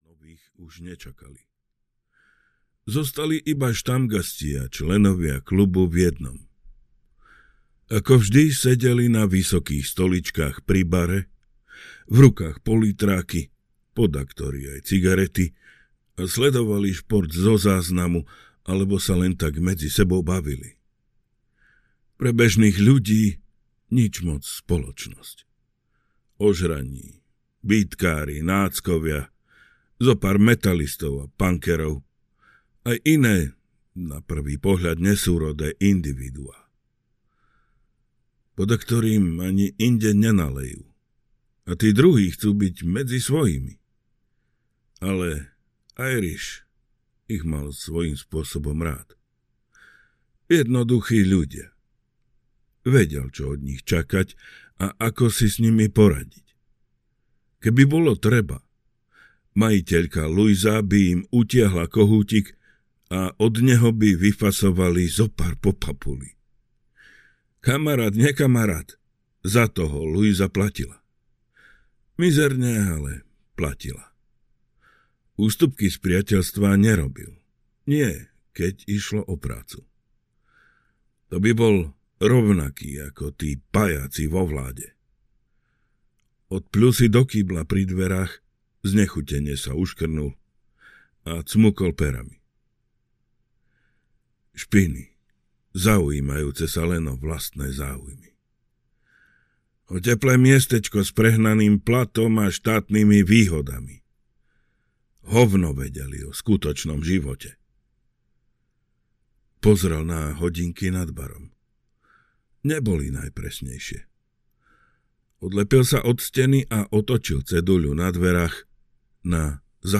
Štvanec audiokniha
Ukázka z knihy